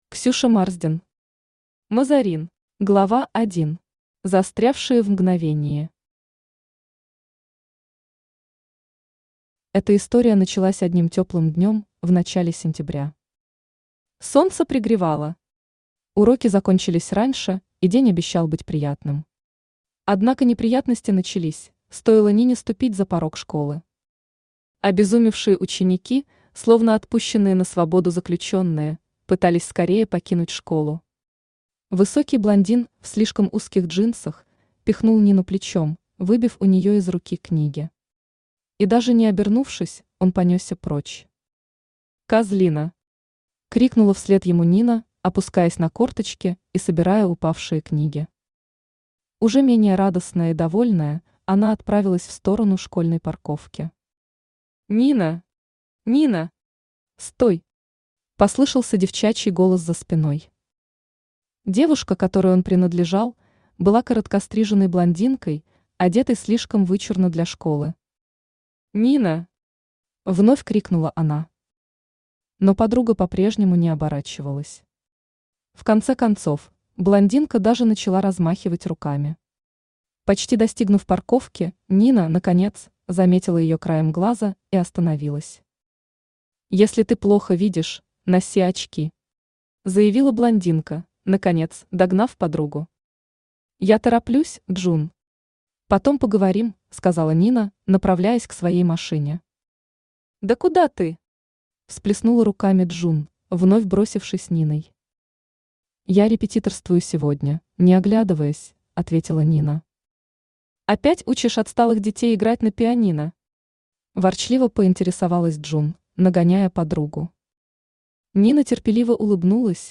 Аудиокнига Мазарин | Библиотека аудиокниг
Aудиокнига Мазарин Автор Ксюша Марсден Читает аудиокнигу Авточтец ЛитРес.